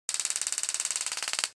Taser Shock 5
Taser Shock 5 is a free sfx sound effect available for download in MP3 format.
yt_N0YvcWghUfo_taser_shock_5.mp3